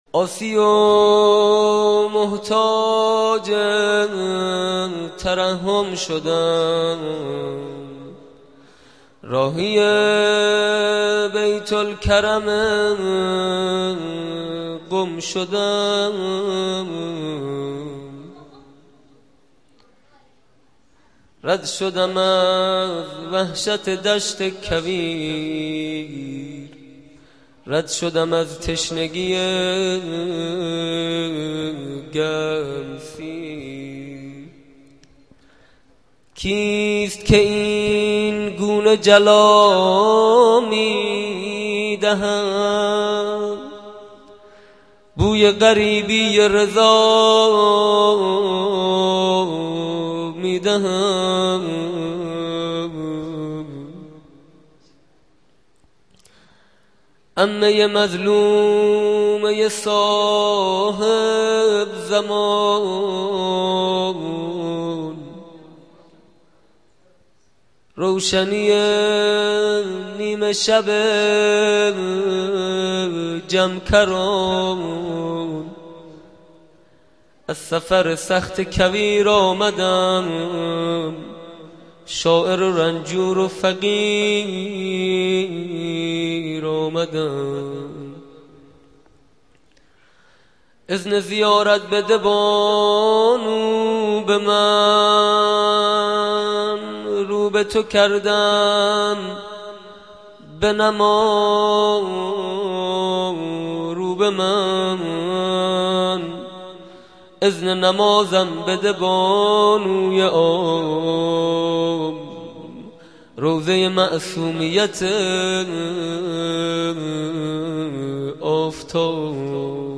مناجات پایانی